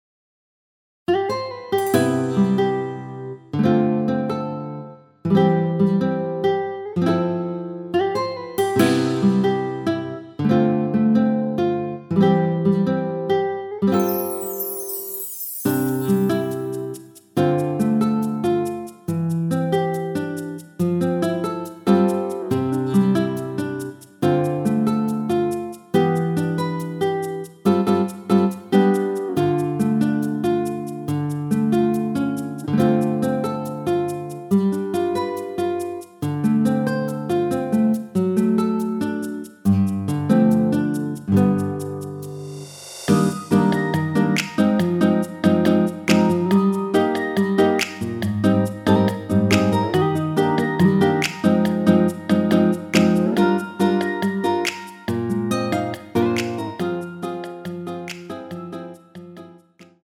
MR입니다.
원키에서(+2)올린 MR입니다.
앞부분30초, 뒷부분30초씩 편집해서 올려 드리고 있습니다.
중간에 음이 끈어지고 다시 나오는 이유는